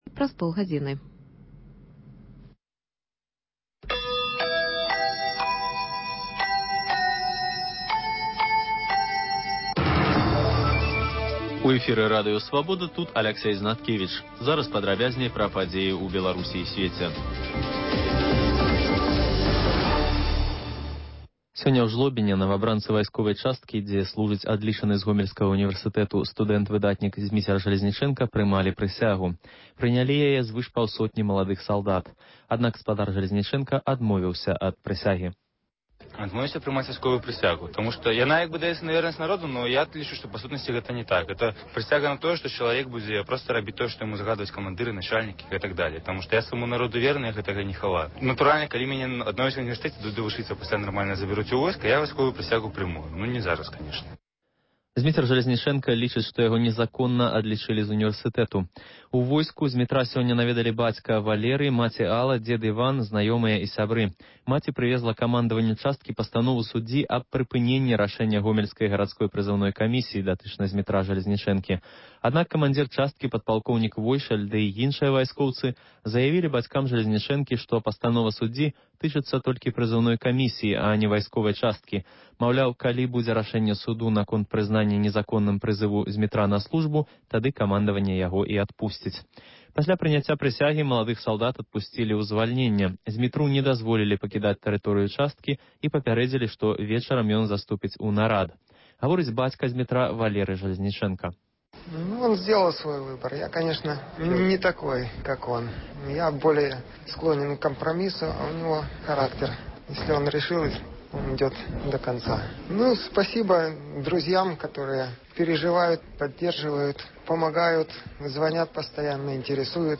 Паведамленьні нашых карэспандэнтаў, званкі слухачоў, апытаньні на вуліцах беларускіх гарадоў і мястэчак.